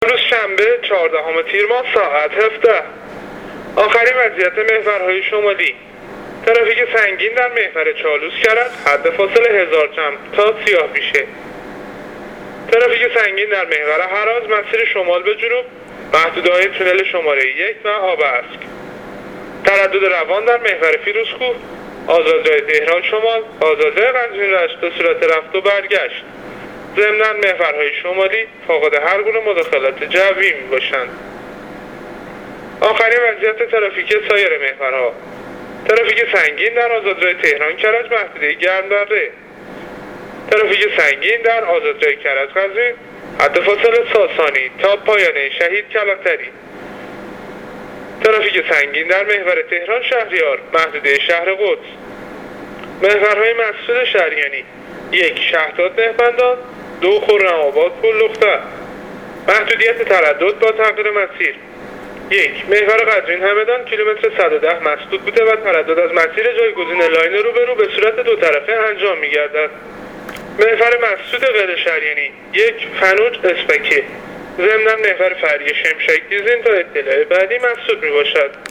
گزارش رادیو اینترنتی از وضعیت ترافیکی جاده‌ها تا ساعت ۱۷ شنبه ۱۴ تیر